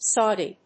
音節Sau・di 発音記号・読み方
/sάʊdi(米国英語), ˈsɔ:di:(英国英語)/